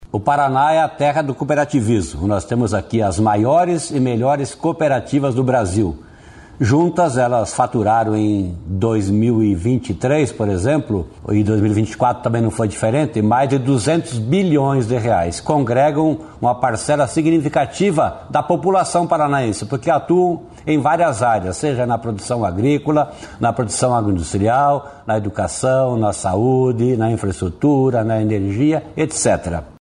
Caso seja aprovada, a medida incluirá a possibilidade de parcelamento dos débitos tributários, multas e juros com descontos de até 95% do valor. O secretário de estado da Fazenda, Norberto Ortigara, aponta que o incentivo ao cooperativismo é importante.